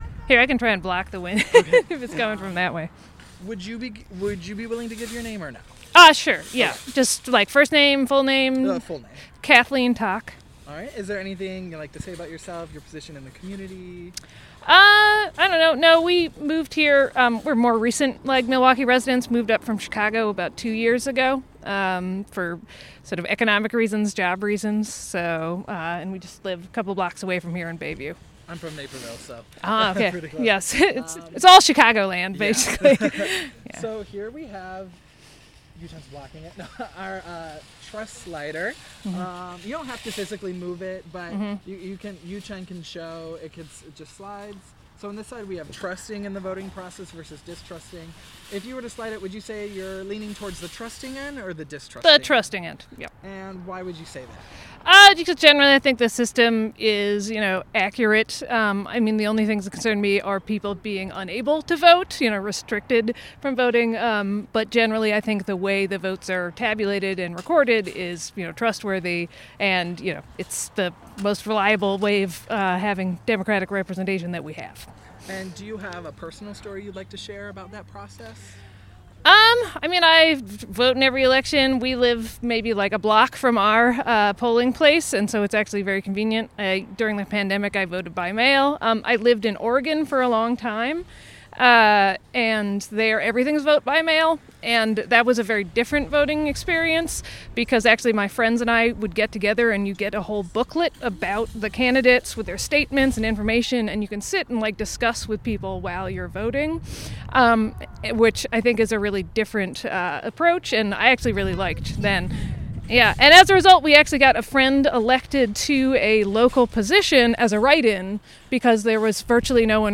Location Outpost Natural Foods